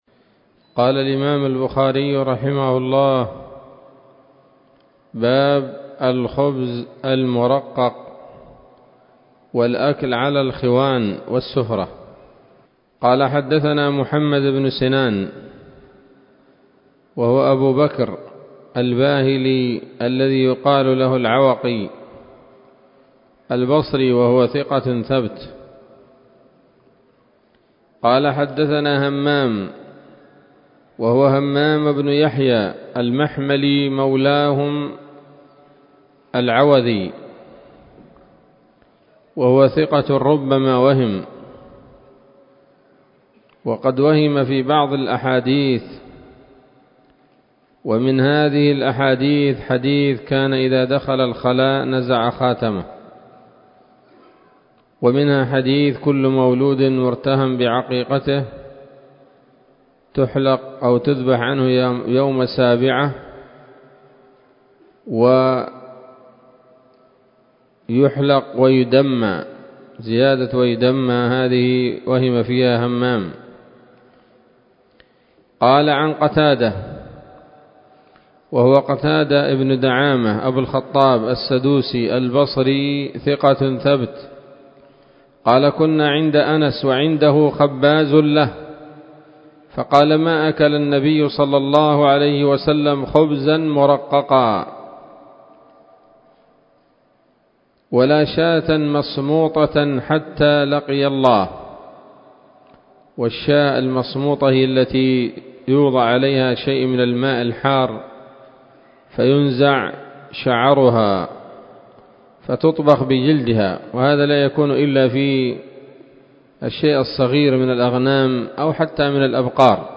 الدرس السادس من كتاب الأطعمة من صحيح الإمام البخاري